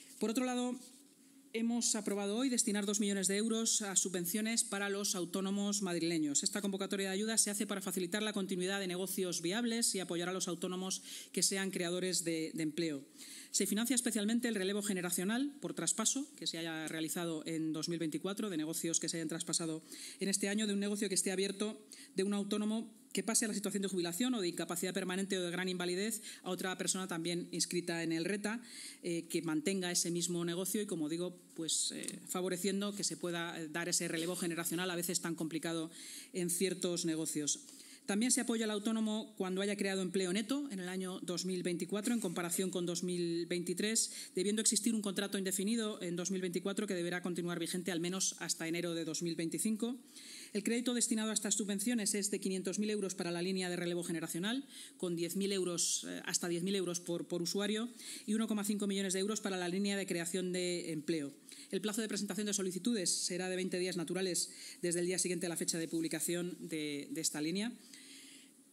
Nueva ventana:La vicealcaldesa y portavoz municipal, Inma Sanz: